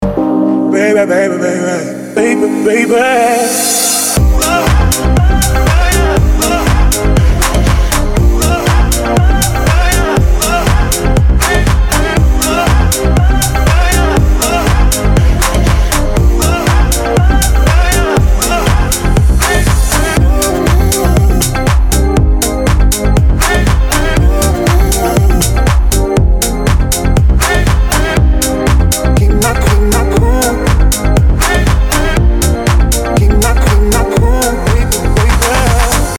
• Качество: 320, Stereo
мужской голос
deep house
Стиль: indie dance